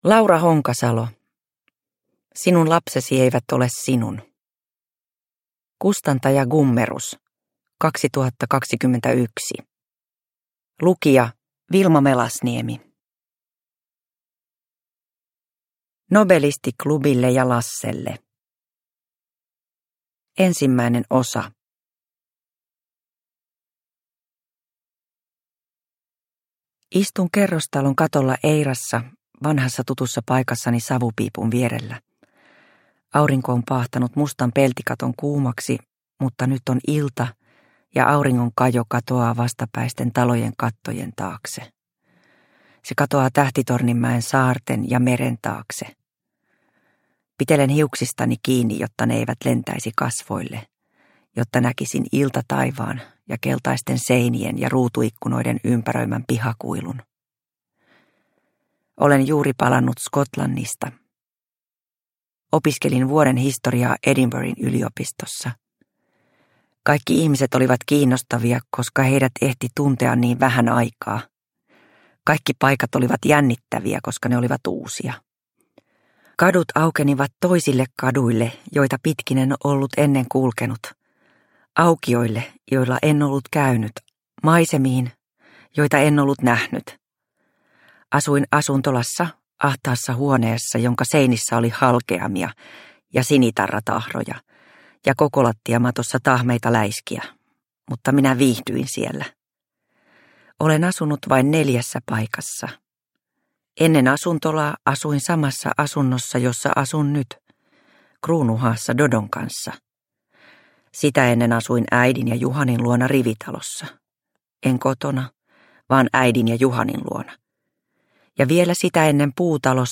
Sinun lapsesi eivät ole sinun – Ljudbok – Laddas ner